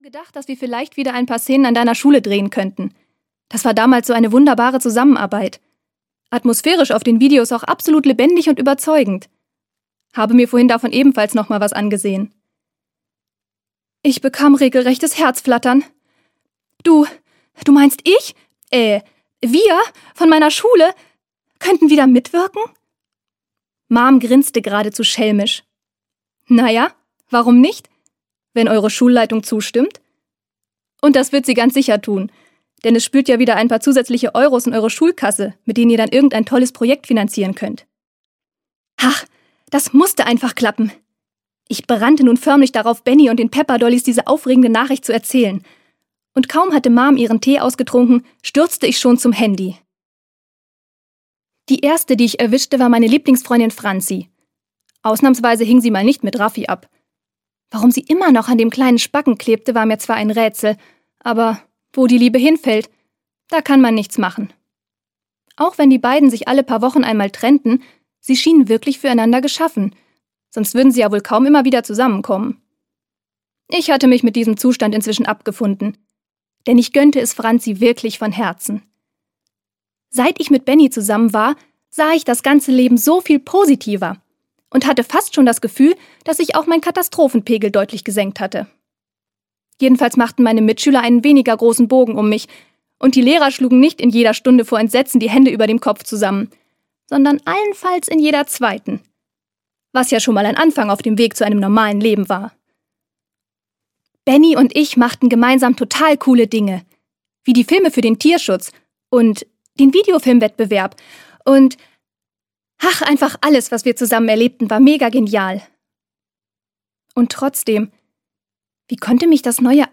Freche Mädchen: Popstars & andere Katastrophen - Bianka Minte-König - Hörbuch